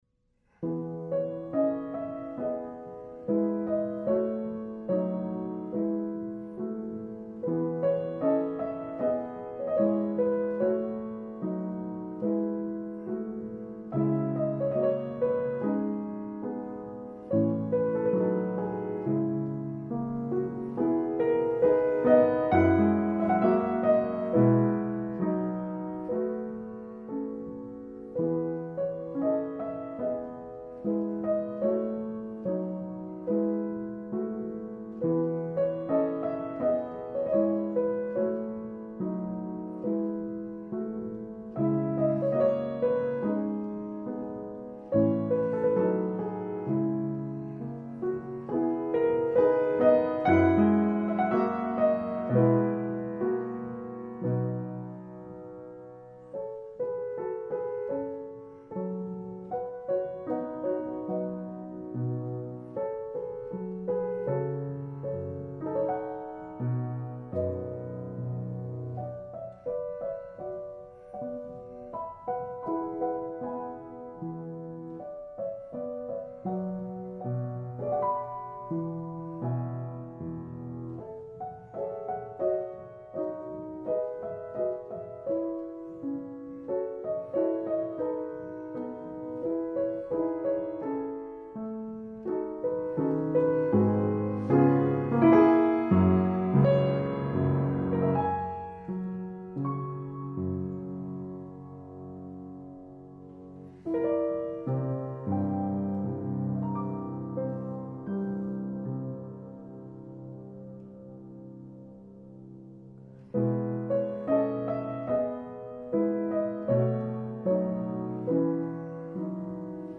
Feurich Upright Piano, 1918, Unequal Temperament